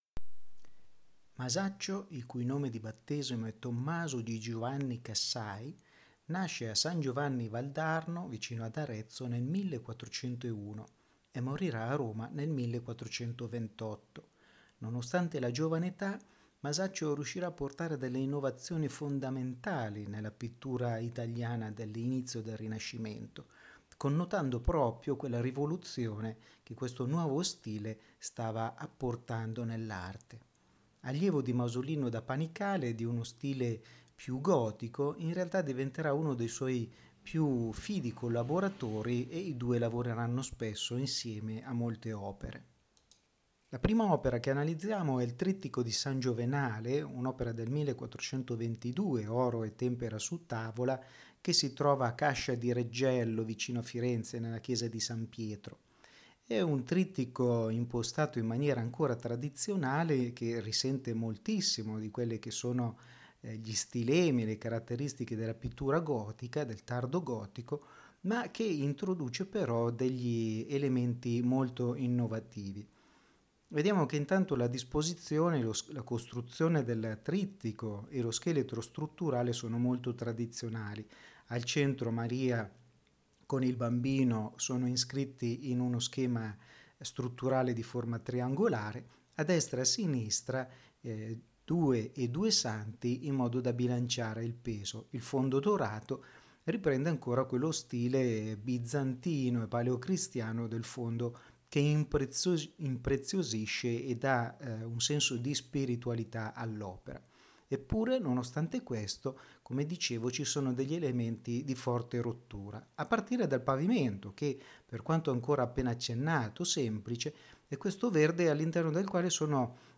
Ascolta la lezione audio dedicata a Masaccio